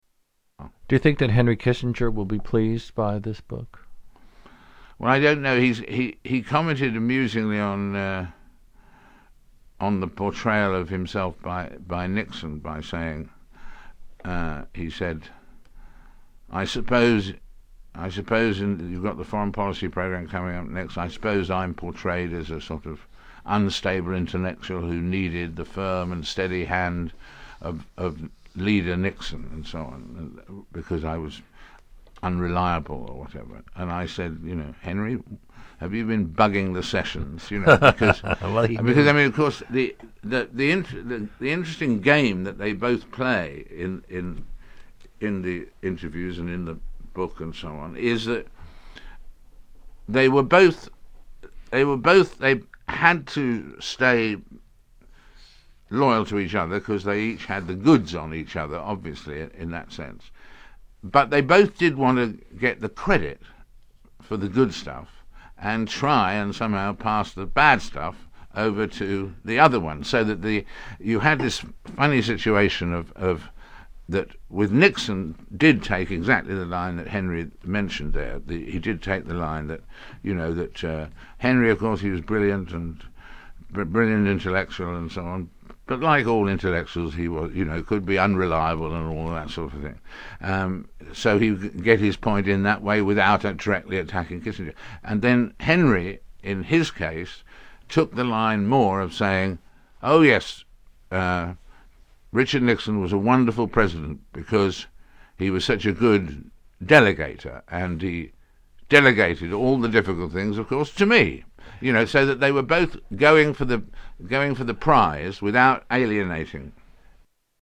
Tags: Historical Frost Nixon Interview Audio David Frost Interviews Richard Nixon Political